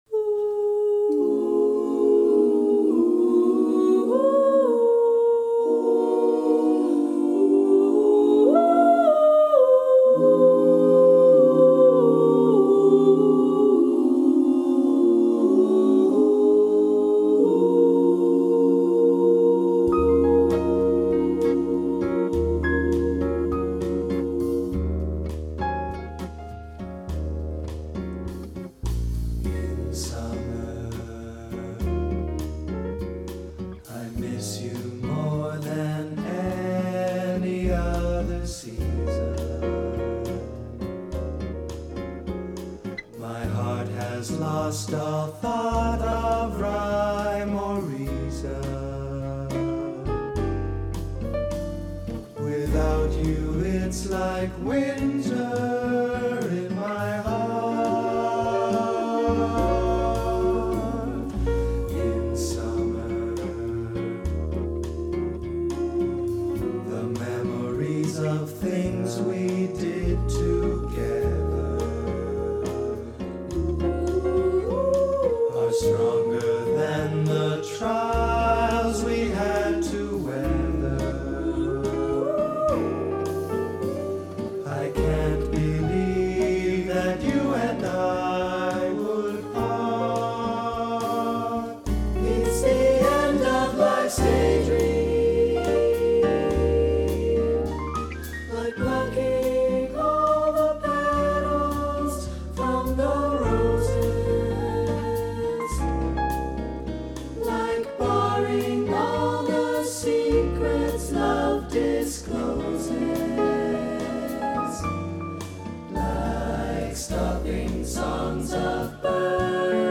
S A T B /rhythm section